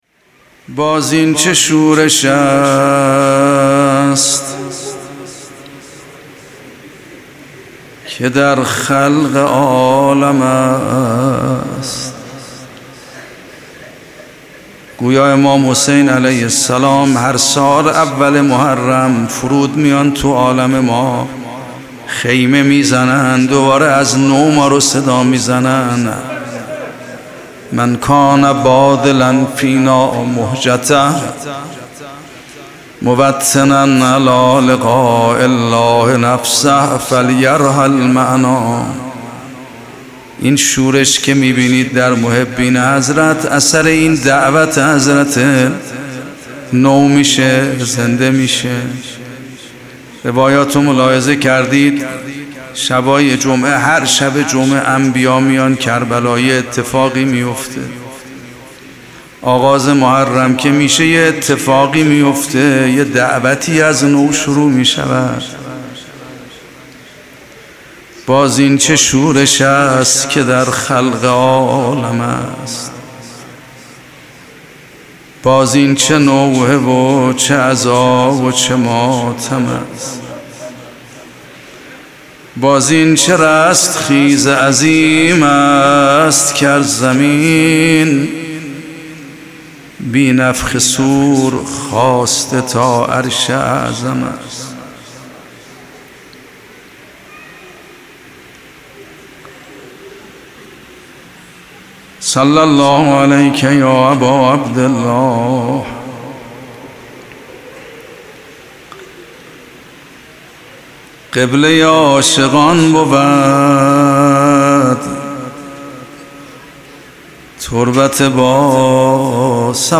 روضه خوانی استاد میرباقری در شب اول ماه محرم 1401
در این بخش از ضیاءالصالحین، به مناسبت ایام عزاداری سرور و سالار شهیدان، روضه امام حسین علیه السلام را در شب اول محرم 1401 با نوای دلنشین آیت الله سید محمد مهدی میرباقری به مدت 10 دقیقه با عاشقان حضرت اباعبدالله الحسین علیه السلام به اشتراک می گذاریم.